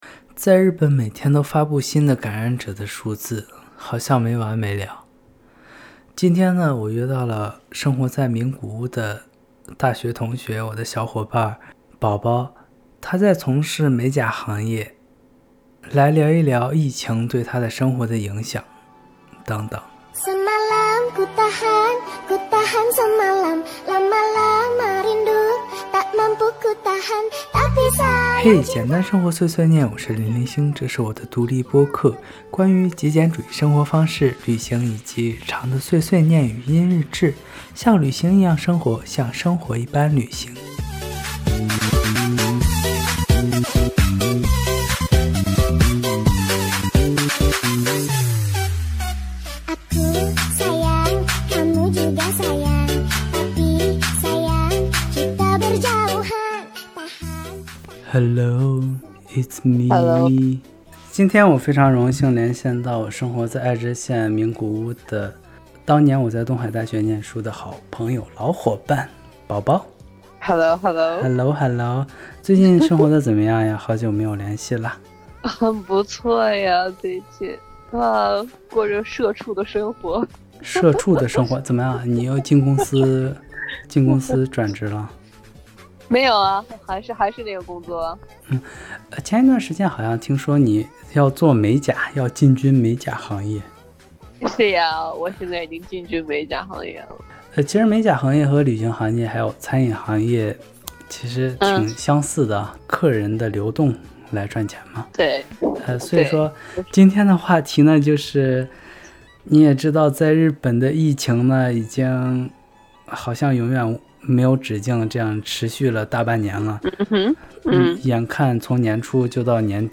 【概要】这个系列将和生活在日本的小伙伴们，对谈日本的疫情状况，以及对于我们工作和生活的现实影响。